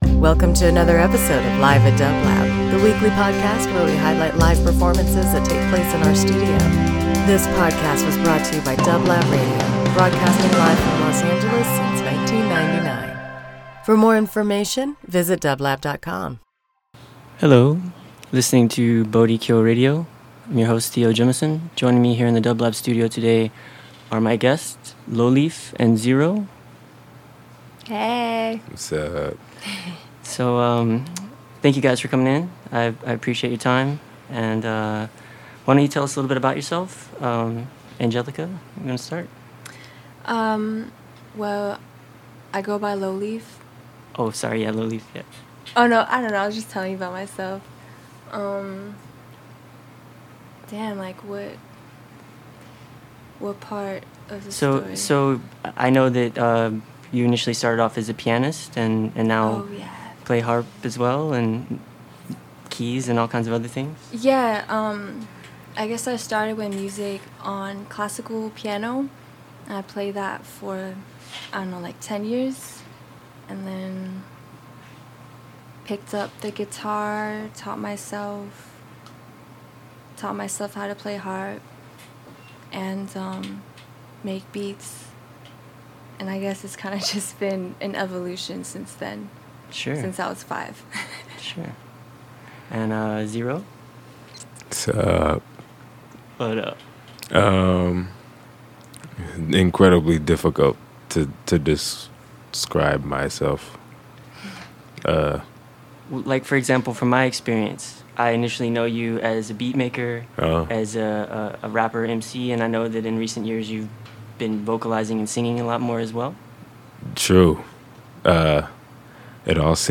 Ambient Beats Hip Hop